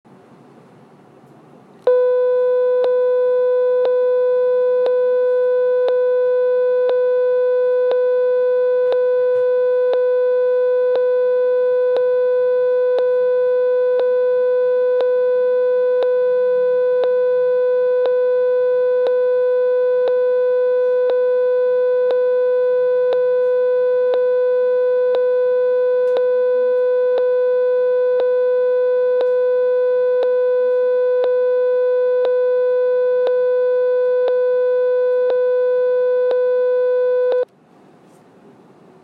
1)通道数：1 (单声道)
测试输入： 正弦音
我尝试播放500 Hz的正弦音。
但我每隔1秒就会听到咔哒声。